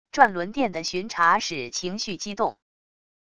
转轮殿的巡察使情绪激动wav音频